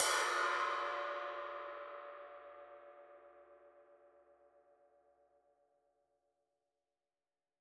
Index of /musicradar/Cymbals/Trash Crashes
CYCdh_TrashC-04.wav